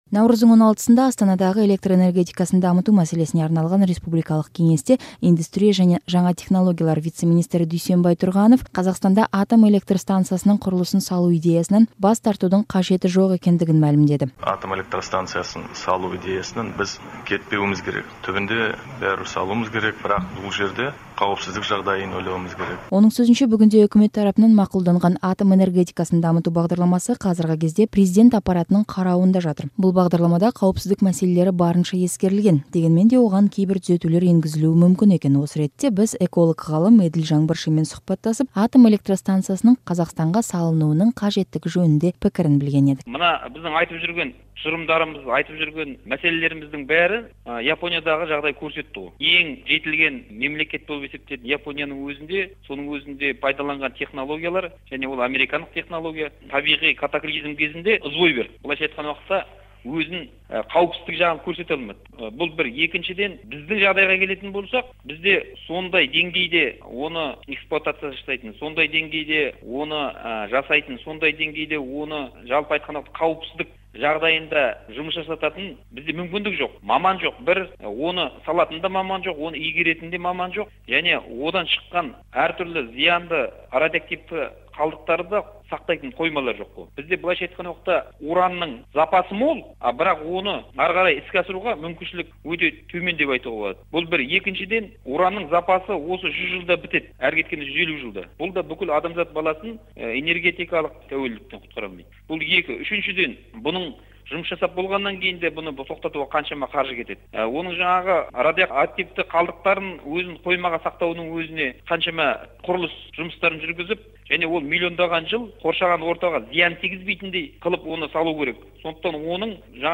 Тәуелсіз эколог-ғалыммен АЭС туралы радио-сұқбат